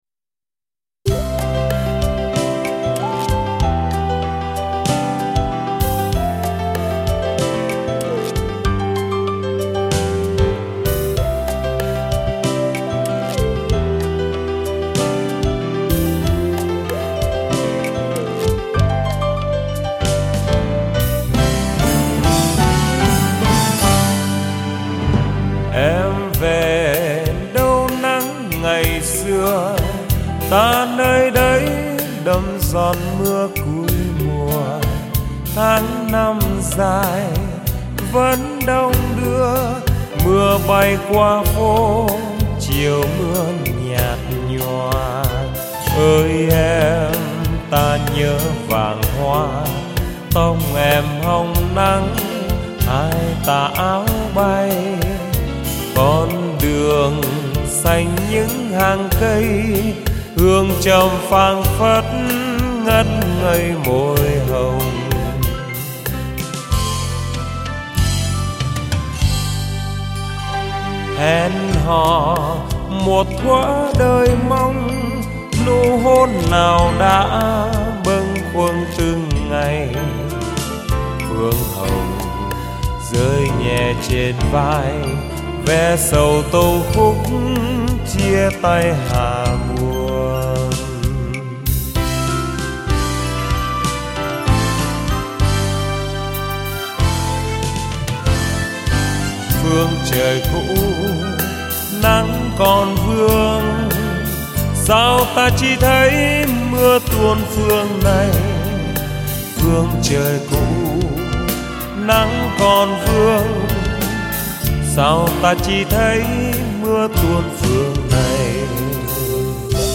tinh ca